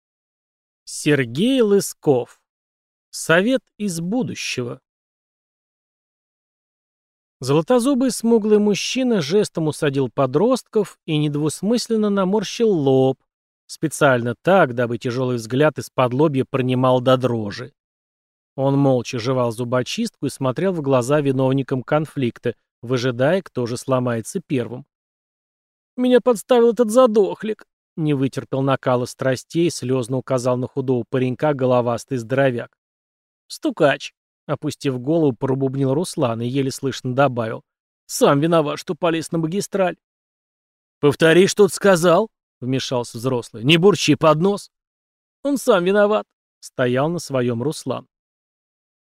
Аудиокнига Совет из будущего | Библиотека аудиокниг
Прослушать и бесплатно скачать фрагмент аудиокниги